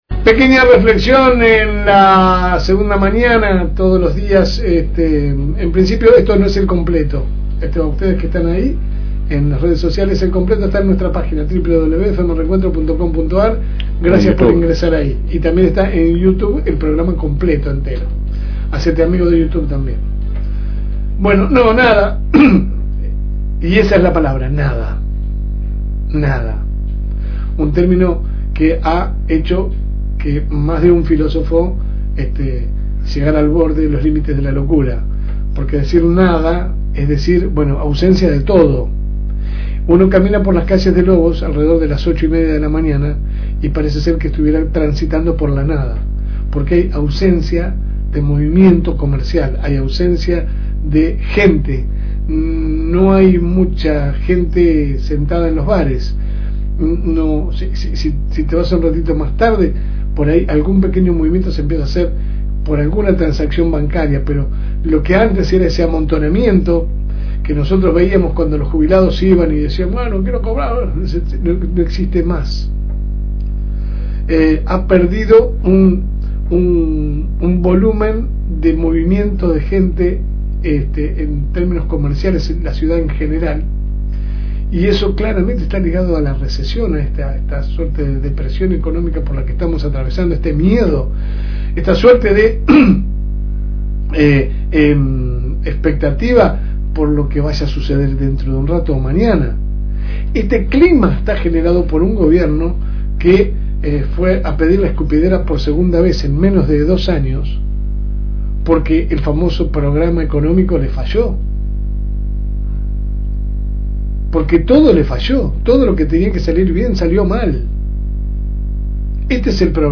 De su programa LA SEGUNDA MAÑANA que sale por el aire de la FM 102.9